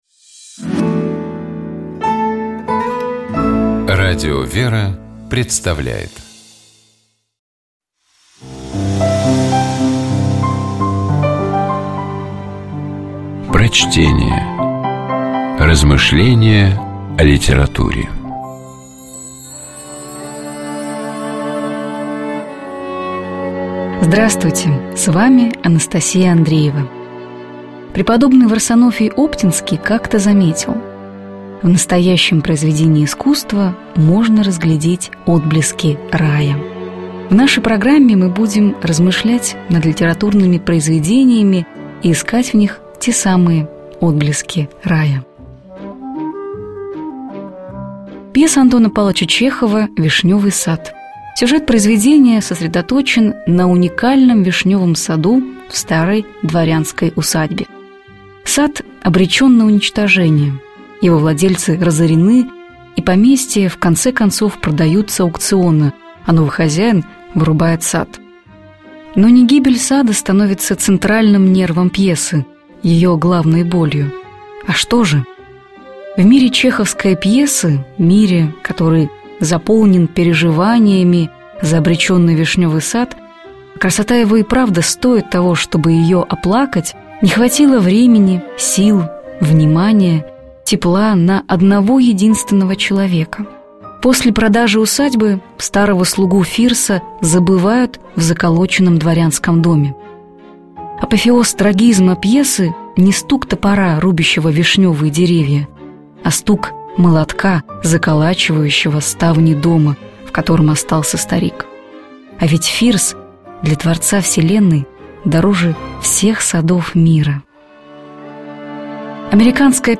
Prochtenie-A_-Chehov_-_Vishnevyj-sad__-O-Firsah-i-zvezdah.mp3